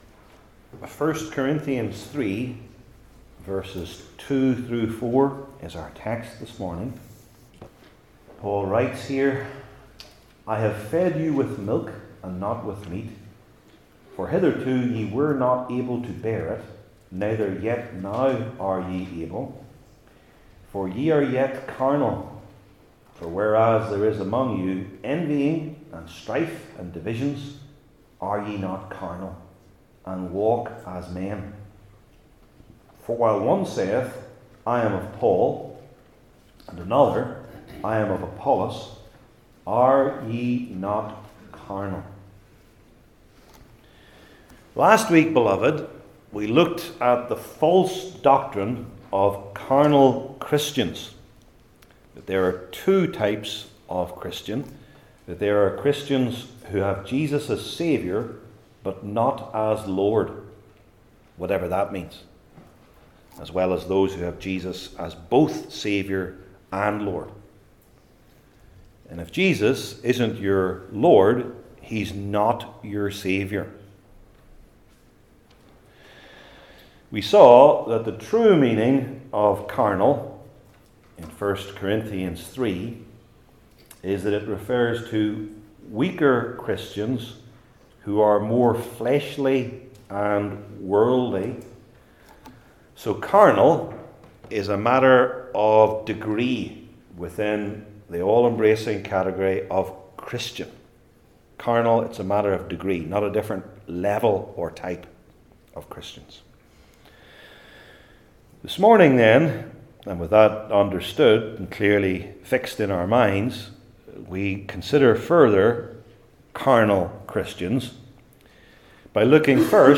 Church Divisions and Christian Ministers Passage: I Corinthians 3:2-4 Service Type: New Testament Sermon Series I. The Proof II.